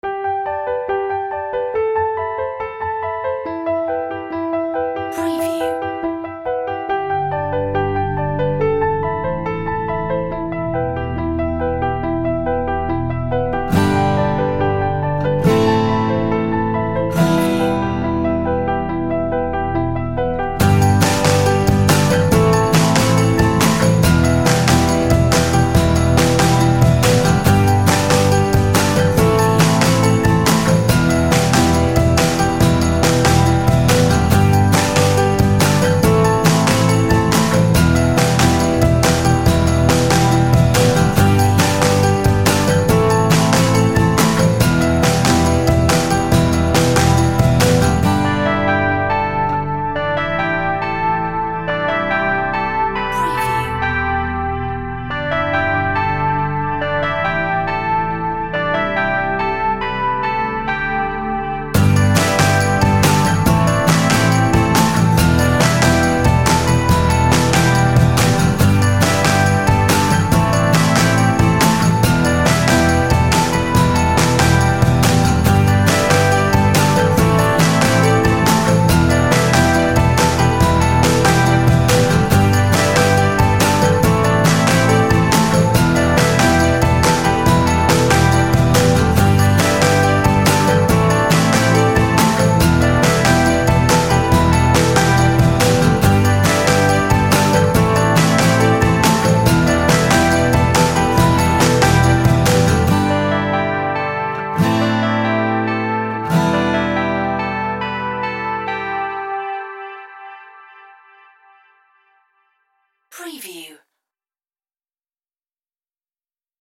Happy nostalgic music